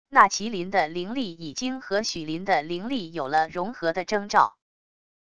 那麒麟的灵力已经和许麟的灵力有了融合的征兆wav音频生成系统WAV Audio Player